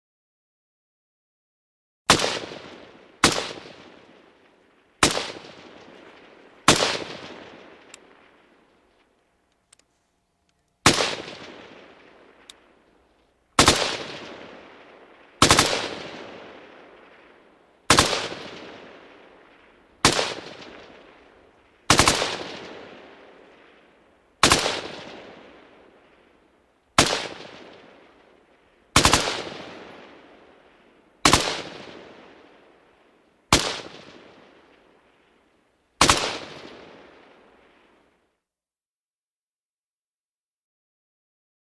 M16|Exterior | Sneak On The Lot
WEAPONS - RIFLES M16: EXT: Single shots & bursts further away.